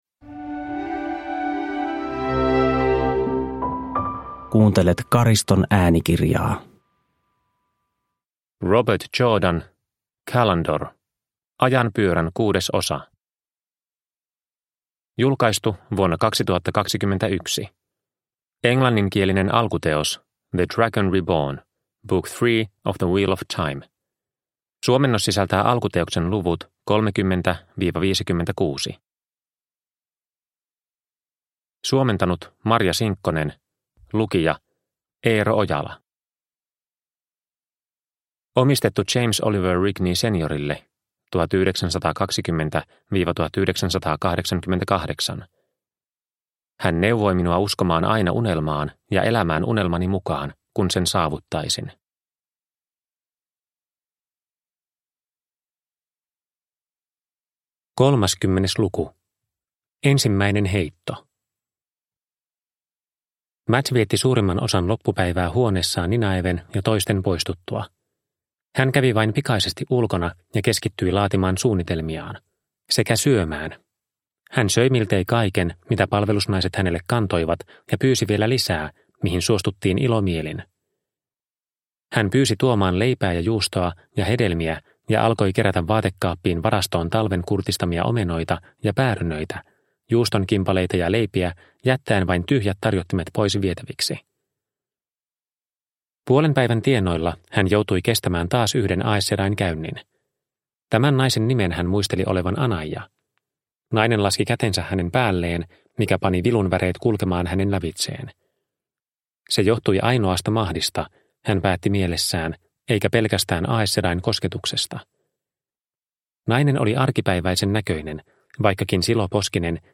Callandor – Ljudbok – Laddas ner